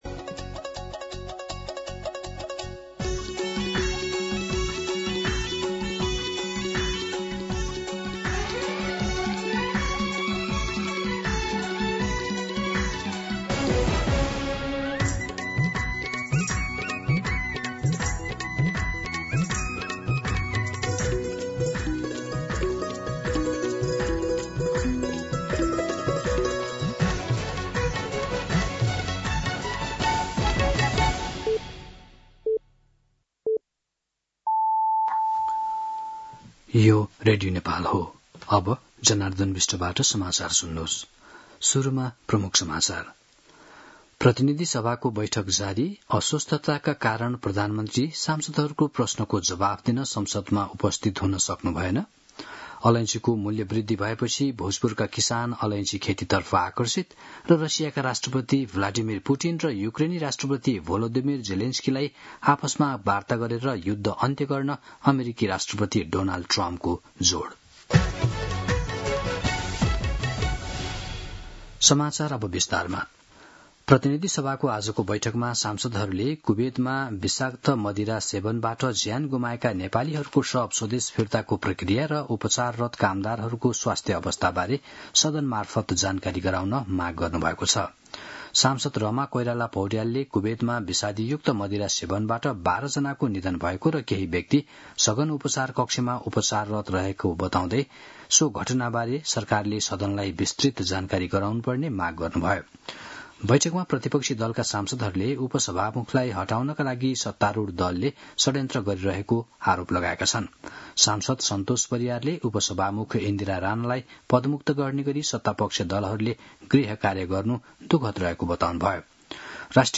दिउँसो ३ बजेको नेपाली समाचार : ४ भदौ , २०८२